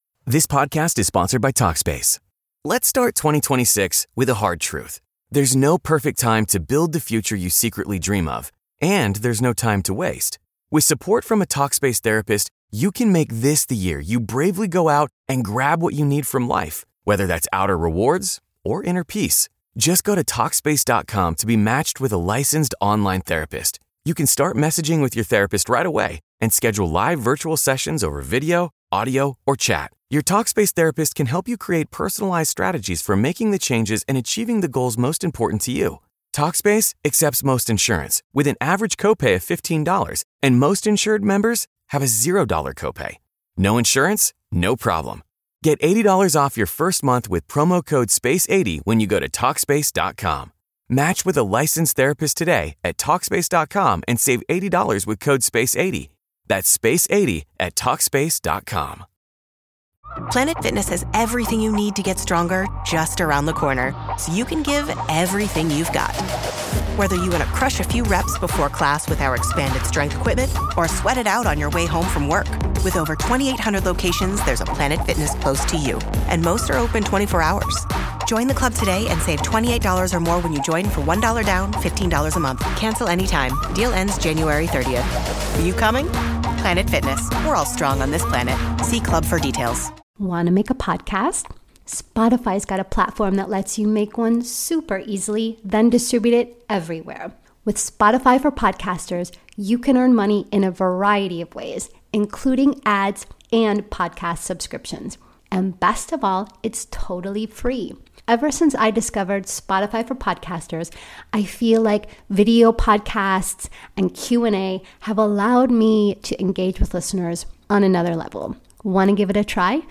Binaural Beats Meditation for Sleep Podcast - 432 HZ Miracle Tone | Free Listening on Podbean App
432 HZ Miracle Tone
Mindfulness and sound healing — woven into every frequency.